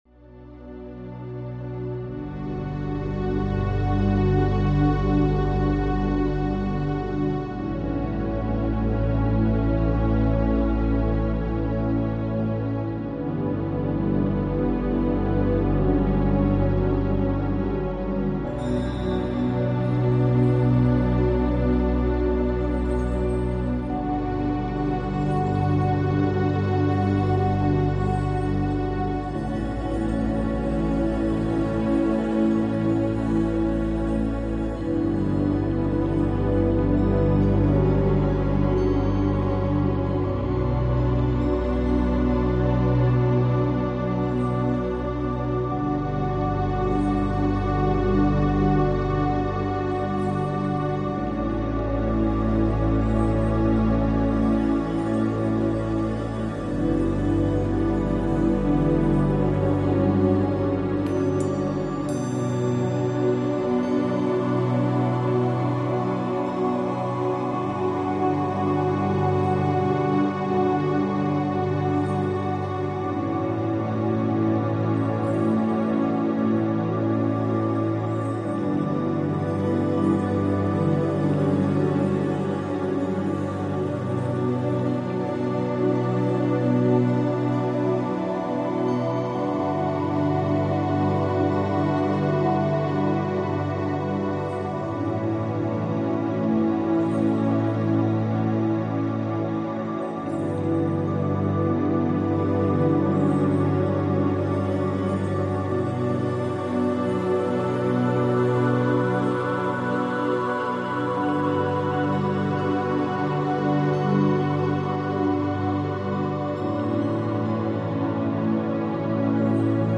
ambient-meditation.mp3